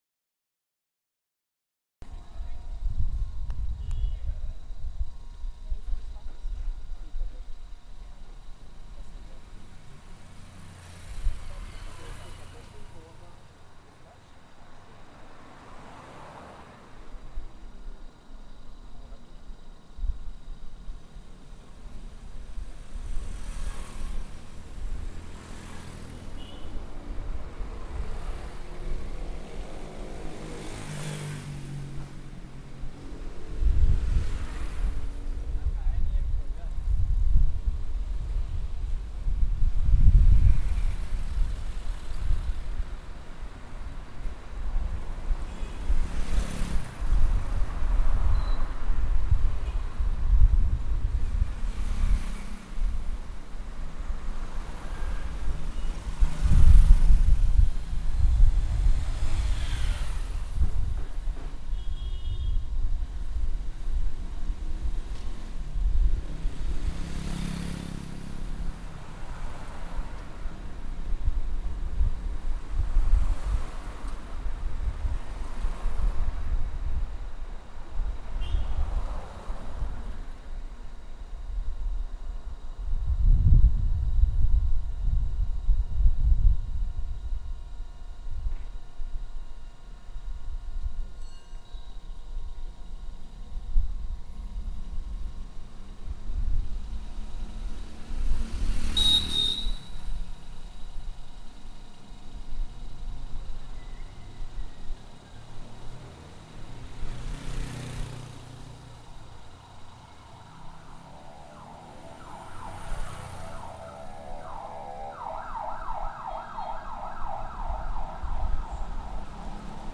Tráfico vehicular, carretillas llevando papel y persianas de los locales cerrando para ir a almorzar. La máquina guillotina, ubicada en la mitad de la calle, resuena fuertemente, además que está en continuo funcionamiento y se detiene únicamente a la hora del almuerzo.